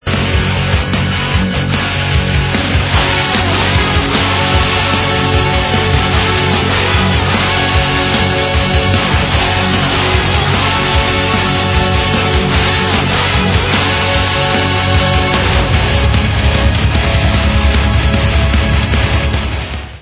Rock/Progressive